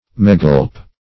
Megilp \Me*gilp"\, Megilph \Me*gilph"\, n. (Paint.)